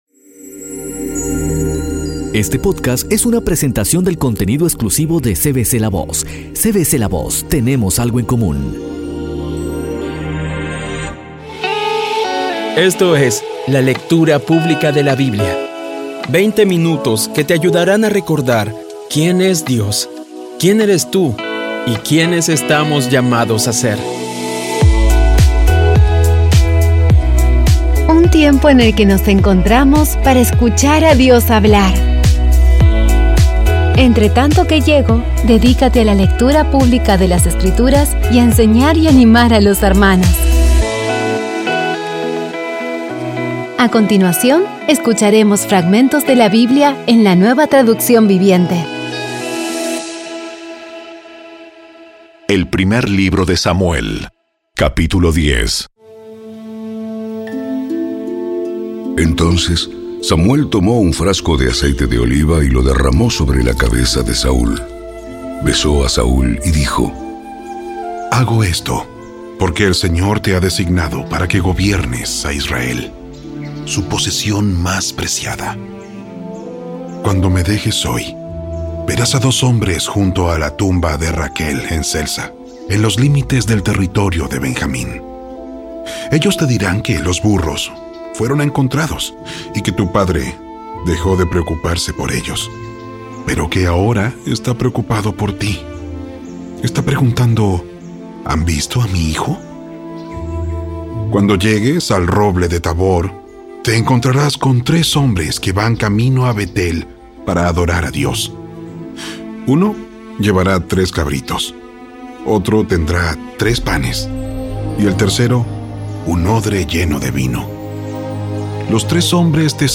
Audio Biblia Dramatizada Episodio 6